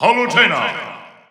The announcer saying Palutena's name in English and Japanese releases of Super Smash Bros. 4 and Super Smash Bros. Ultimate.
Palutena_English_Announcer_SSB4-SSBU.wav